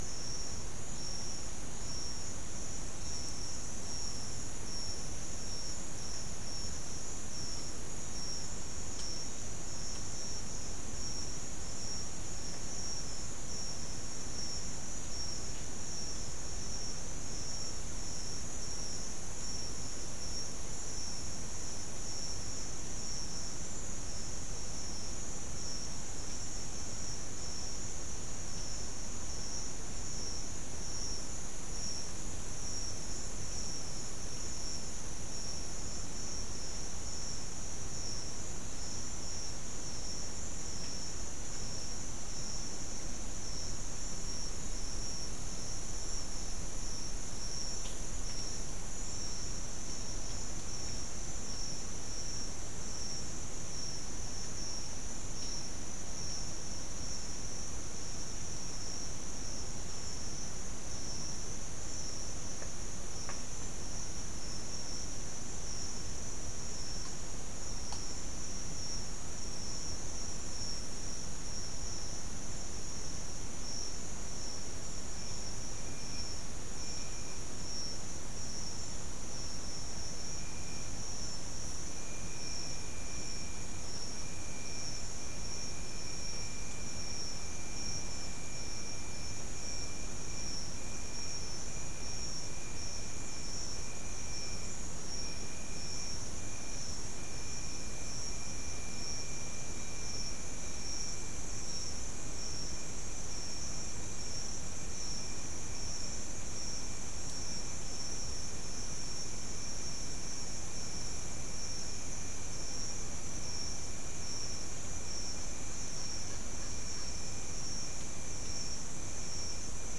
Soundscape
Location: South America: Guyana: Rock Landing: 2
Recorder: SM3